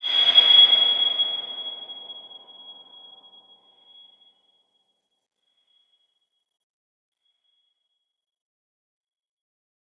X_BasicBells-G#5-mf.wav